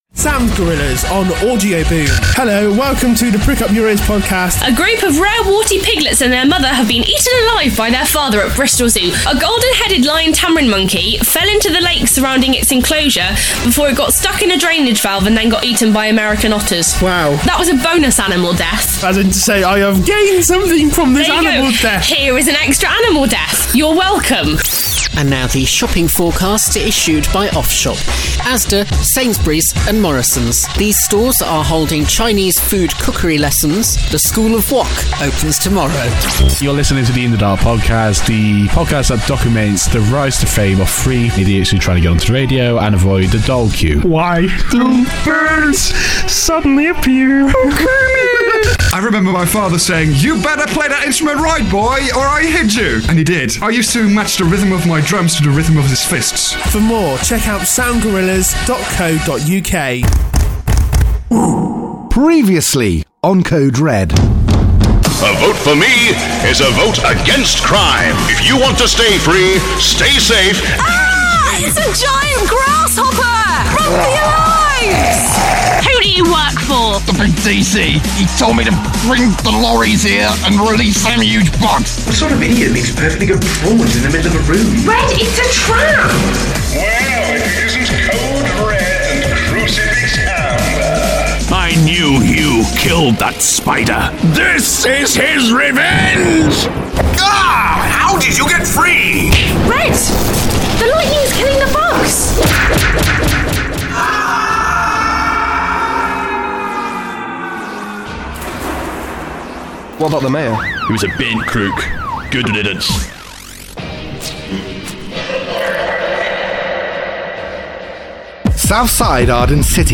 Episode 2 of our independently produced superhero audio comic Code Red. Episode two sees the return of Venom Black and Jimmy Knuckles, as they try to get their revenge on Code Red and Crucifix Amber.